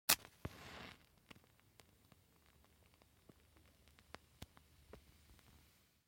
دانلود آهنگ آتش 23 از افکت صوتی طبیعت و محیط
دانلود صدای آتش 23 از ساعد نیوز با لینک مستقیم و کیفیت بالا
جلوه های صوتی